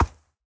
minecraft / sounds / mob / horse / soft6.ogg